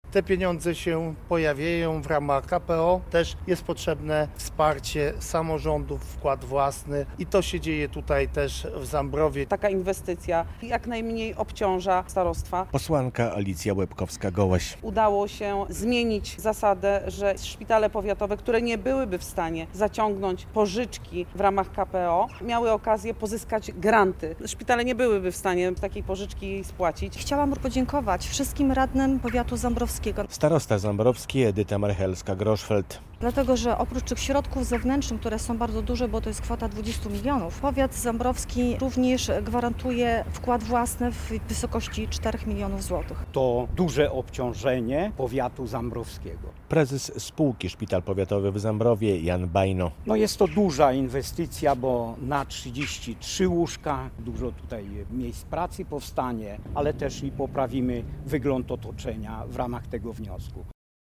Kosztować ma nieco ponad 24 mln zł i rozpocząć działanie od połowy przyszłego roku - o budowie w Zambrowie Zakładu Opiekuńczo-Leczniczego poinformowano podczas konferencji prasowej w tym mieście.